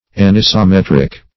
Anisometric \An`i*so*met"ric\, a. [Gr.